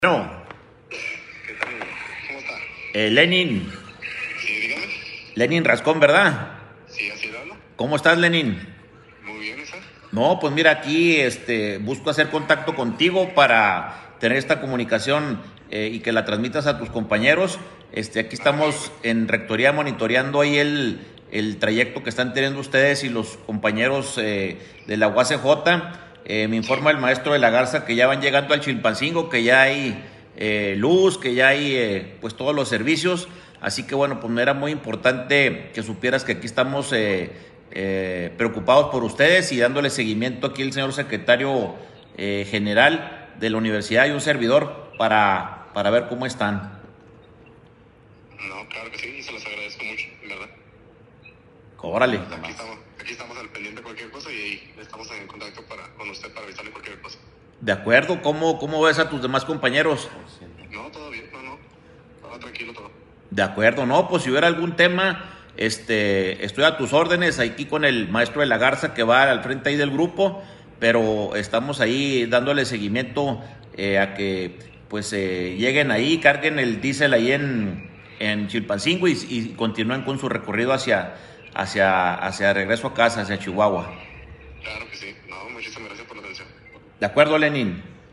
AUDIO: LUIS RIVERA CAMPOS, RECTOR DE LA UNIVERSIDAD AUTÓNOMA DE CHIHUAHUA (UACh)